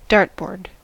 dartboard: Wikimedia Commons US English Pronunciations
En-us-dartboard.WAV